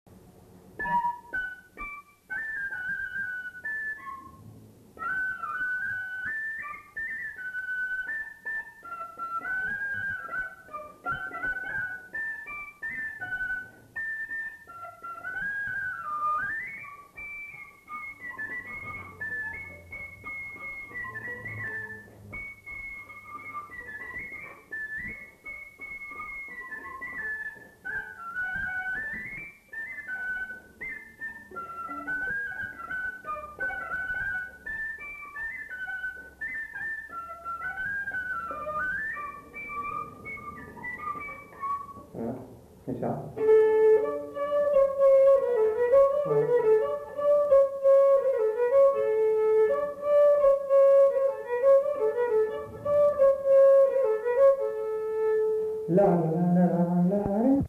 Lieu : Bazas
Genre : morceau instrumental
Instrument de musique : fifre
Danse : scottish